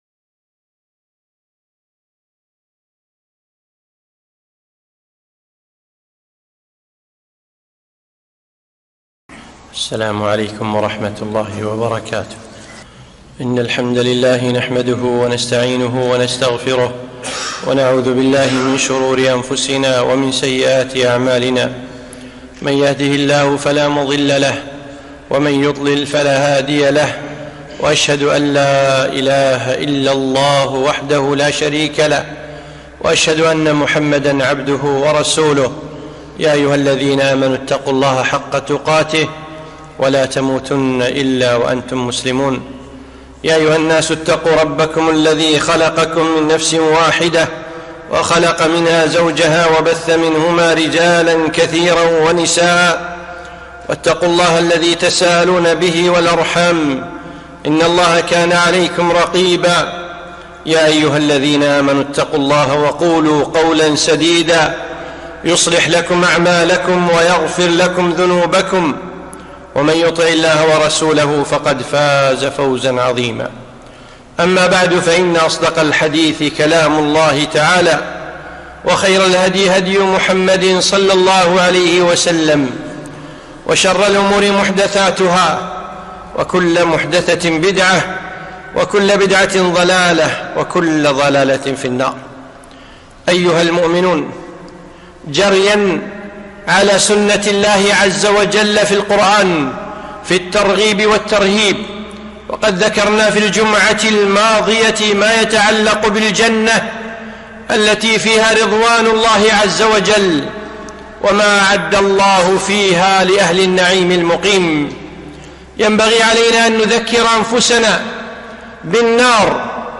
خطبة - ناراً تلظى